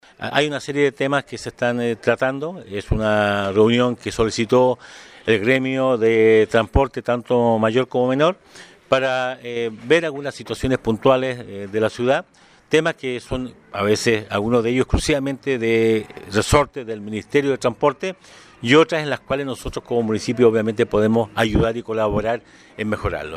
Por su parte, el alcalde de Osorno, Emeterio Carrillo, señaló que fue interesante la reunión, donde se pudieron resolver dudas legítimas de la ciudadanía y los gremios del transporte.
29-septiembre-23-Emeterio-Carrillo-Transporte.mp3